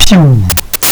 my-gd-game/assets/sfx/shoot3.wav at main
shoot3.wav